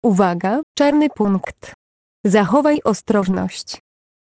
Dźwięki ostrzegawcze Czarny punkt